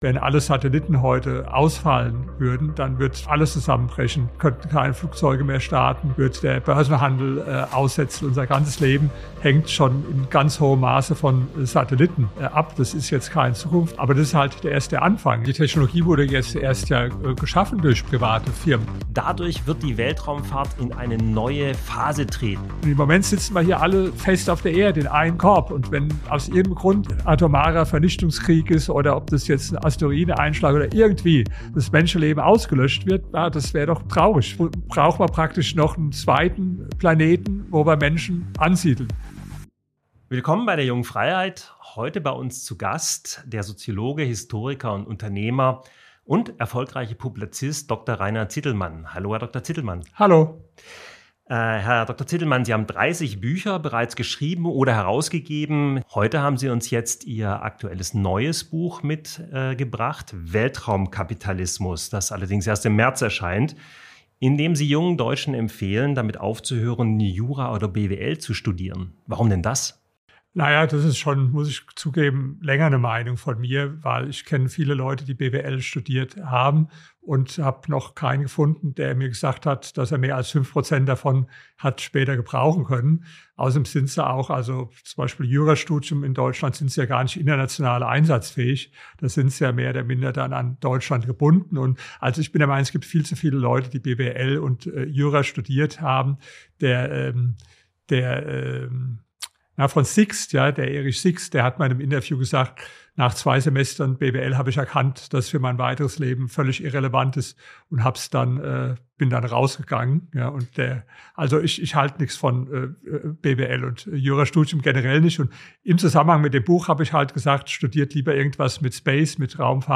Junge Freiheit Interview Weltraumkapitalismus: Private Weltraumunternehmen erobern das All | Rainer Zitelmann im JF-Interview Play episode January 23 1h 14m Bookmarks Episode Description Der Publizist Rainer Zitelmann spricht im JF-Interview über sein neues Buch »Weltraumkapitalismus« und die wachsende Bedeutung der Space Economy. Warum private Unternehmen die Raumfahrt vorantreiben, weshalb Satelliten unseren Alltag bestimmen und wieso er jungen Menschen von Jura und BWL abrät.